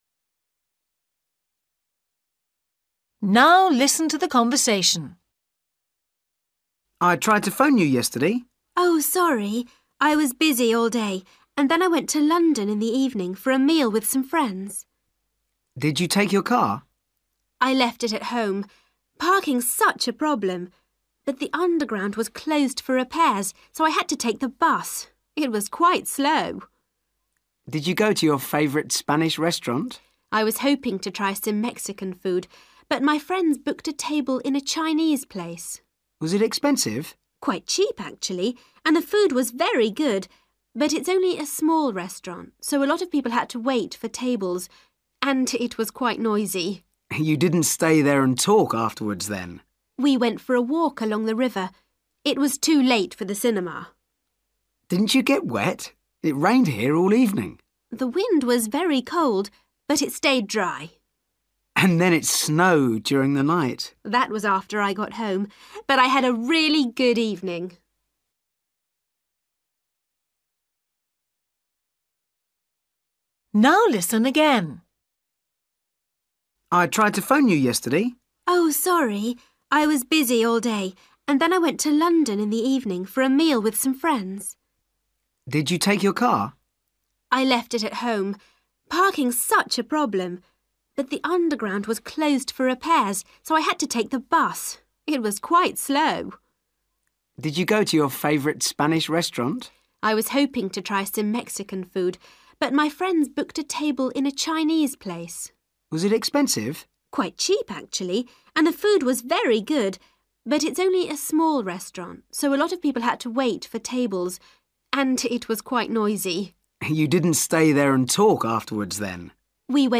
You will hear the conversation twice.